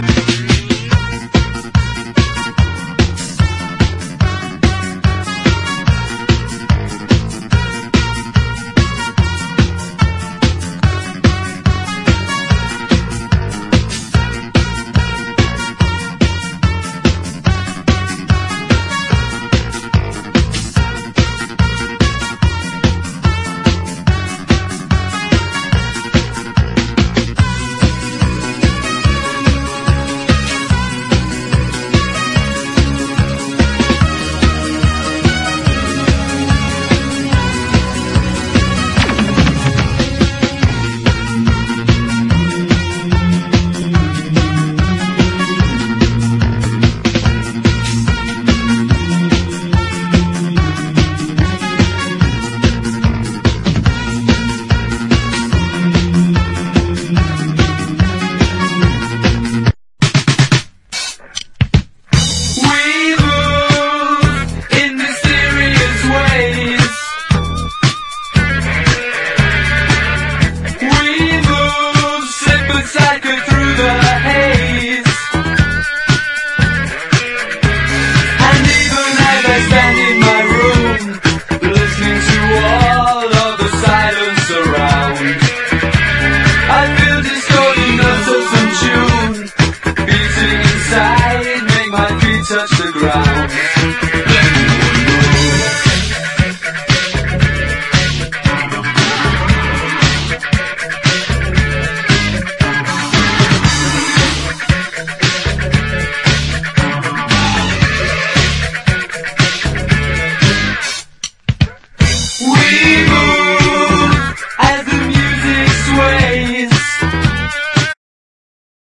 TECHNO POP / ELE POP / SYNTH POP
テクノ・ポップ・ミーツ・スウィングな傑作盤！